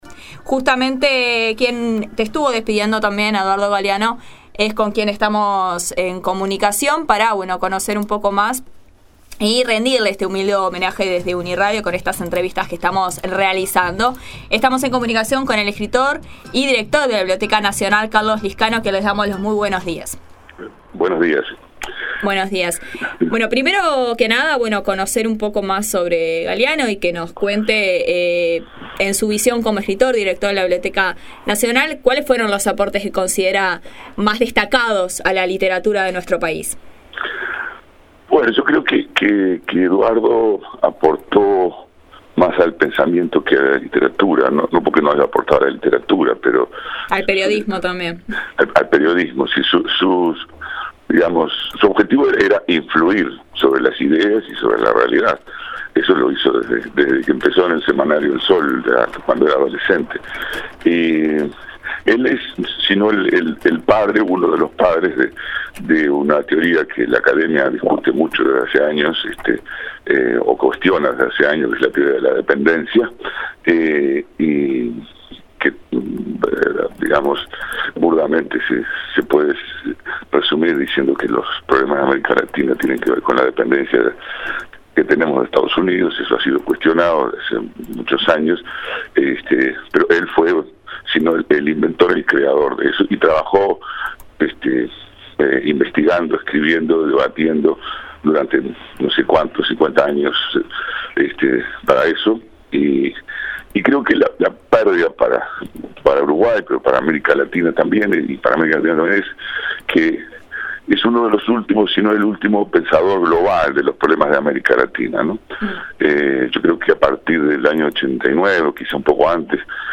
UNI Radio continúa homenajeando al escritor Eduardo Galeano que falleció el 13 de abril de 2015. La Nueva Mañana dialogó con el escritor Carlos Liscano, Director de la Biblioteca Nacional, quien recordó a su colega, destacó su trayectoria, sus aportes al pensamiento latinoamericano y a la cultura así como su rol como divulgador de la historia de América Latina en el resto del mundo, la emotividad de su despedida, entre otros temas.